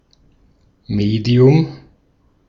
Ääntäminen
US : IPA : [ˈmi.di.əm]